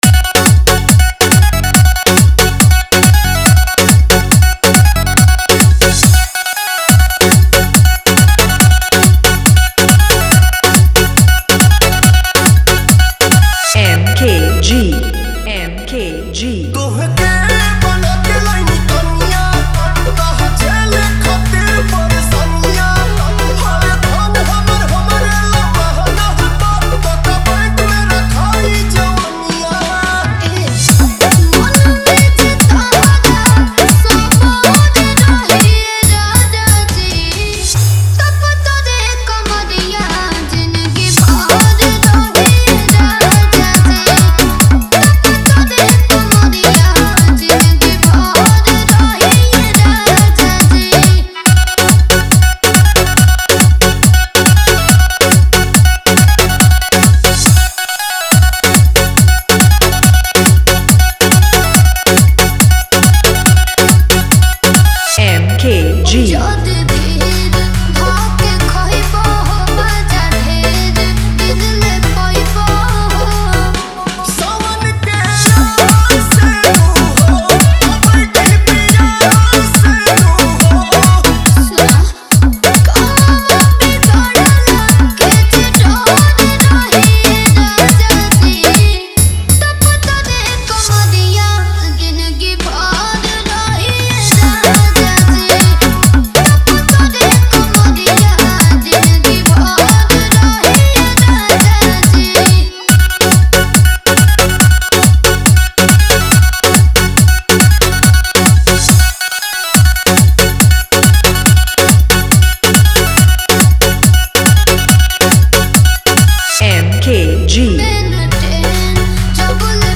Category : Bhojpuri Dj Remix Jhanjhan Bass